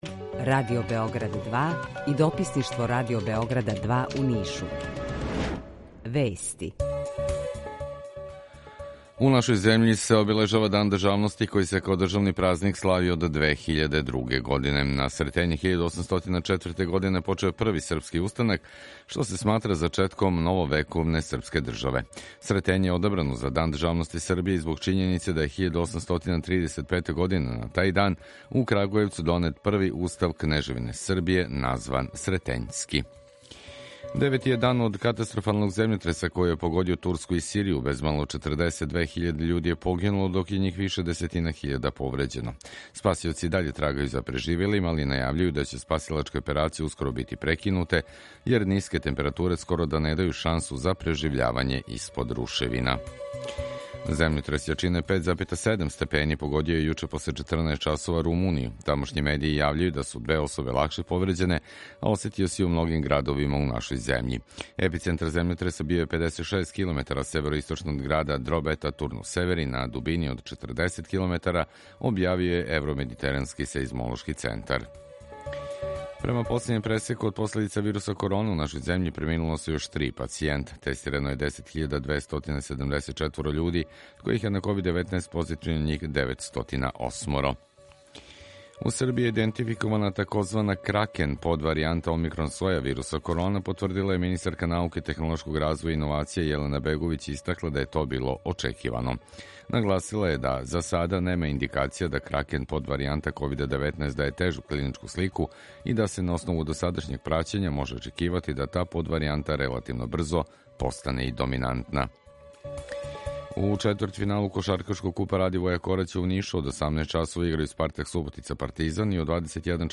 Укључење Радија Грачаница
У два сата, ту је и добра музика, другачија у односу на остале радио-станице.